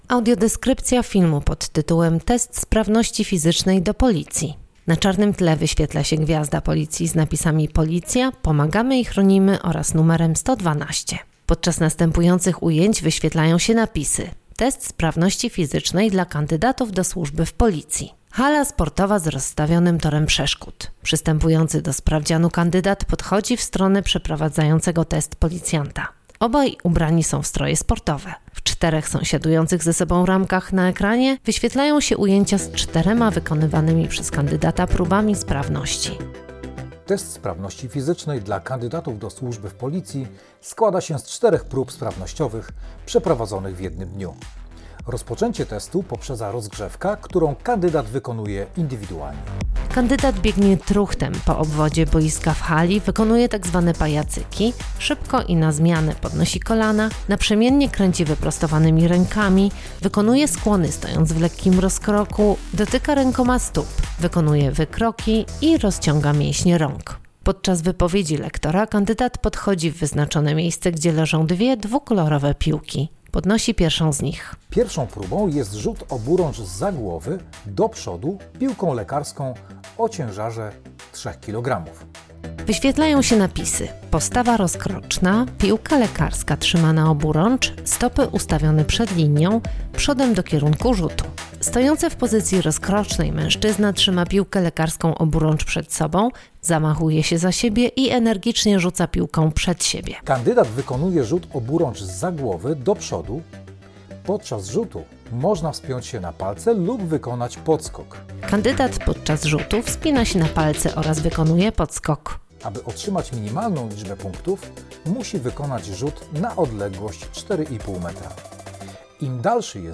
Audiodeskrypcja do filmu (.mp3, 10,46 MB)
test-sprawnosci-fizycznej-do-policji.mp3